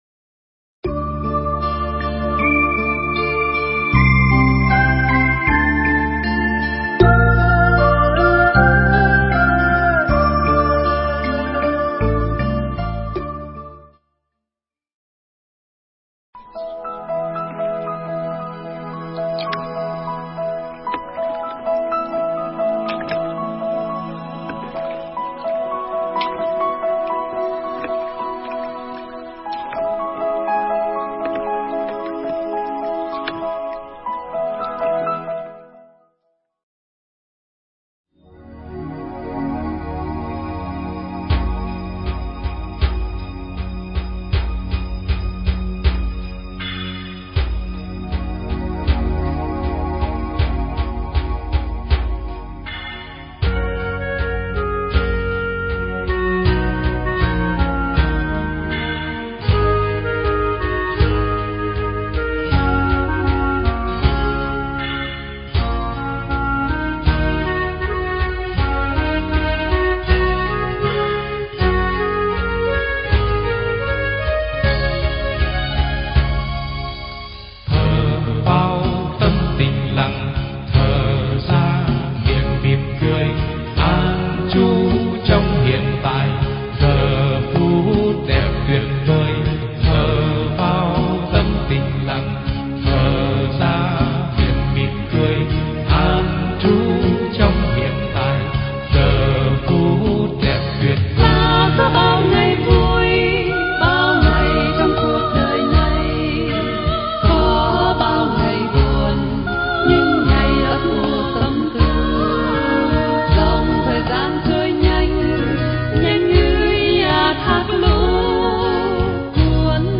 pháp thoại Nuôi Dưỡng Trái Tim
giảng trong ngày lễ Tạ Ơn tại Đạo tràng Mắt Thương Nhìn Đời